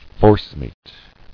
[force·meat]